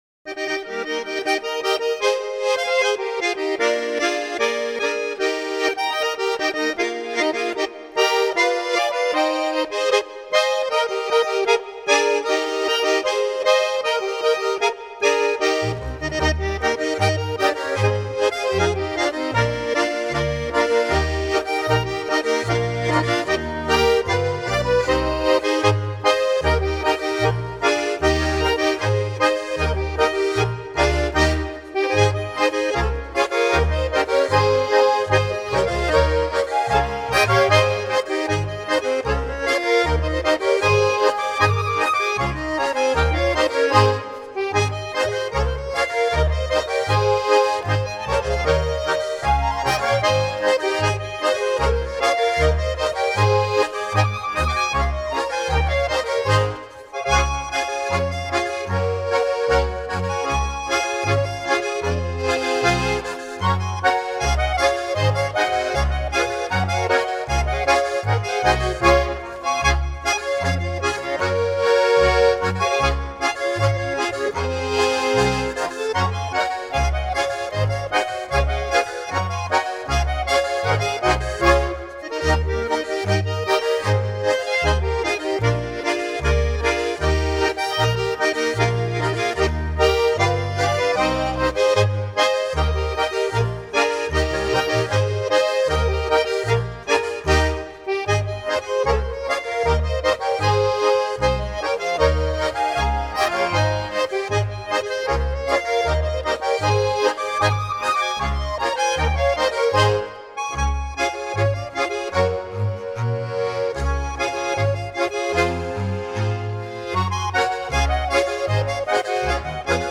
die etwas andere Volksmusik
eine Polka